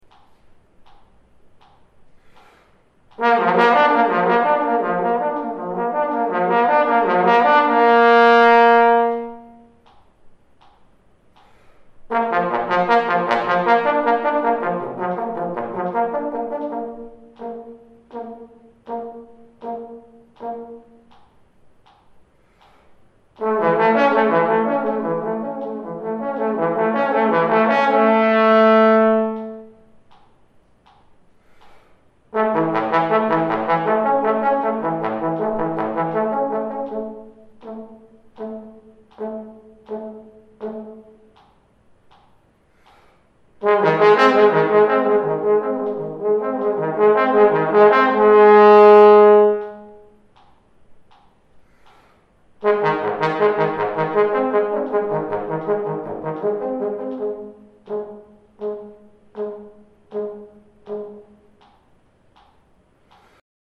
For example, here is a lip slur pattern with dynamics added.
flex-dynamics-edit.mp3